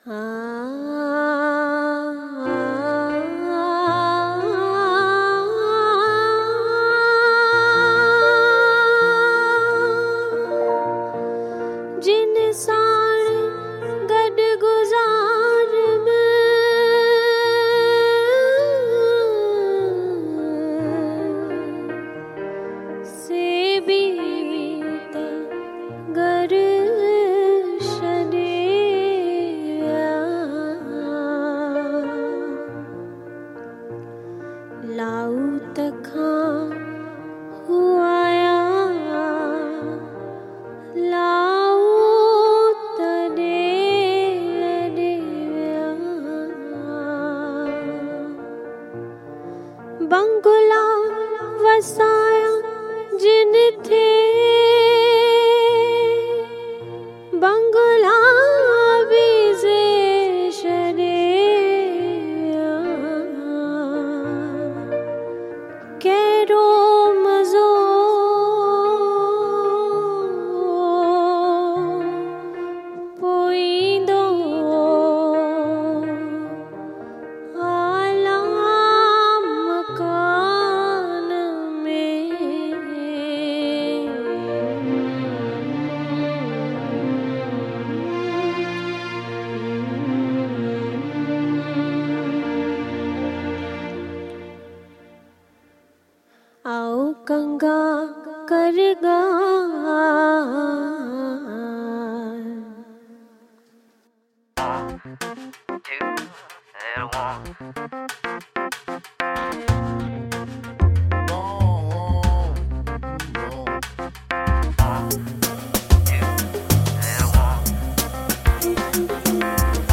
Sindhi songs